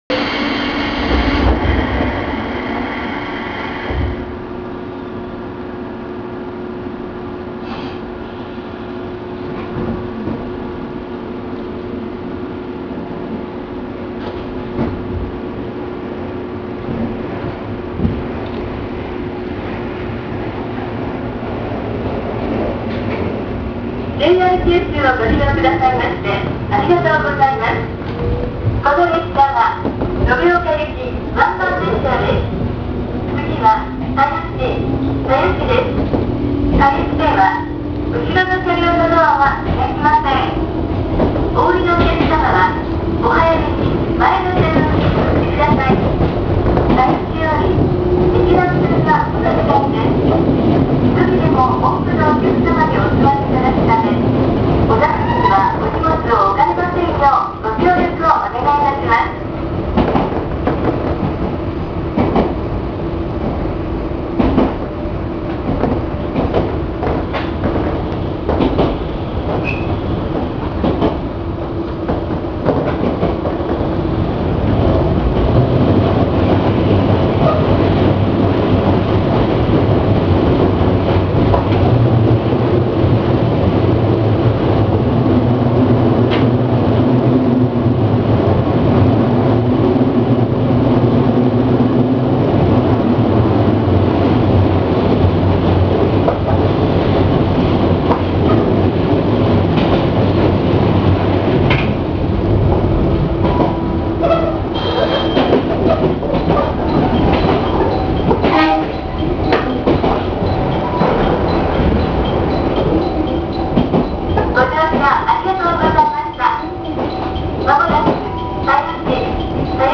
他の国鉄形で例えると205系と同じ音がするため、見た目とのギャップに少し戸惑ってしまいそうです。
・713系走行音
【宮崎空港】宮崎空港→田吉（2分50秒：927KB）
見た目とはギャップの大きい205系の音。この区間は速度も出さずカーブも多かったため、大した音になりませんでした。